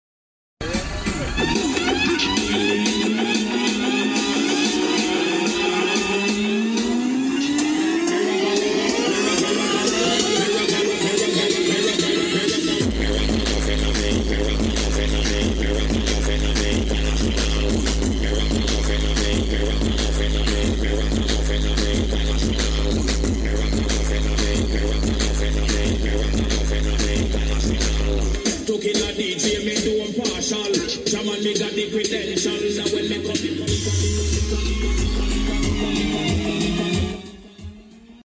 Single 15 in custom rear sound effects free download
Single 15 in custom rear horn loaded enclosure.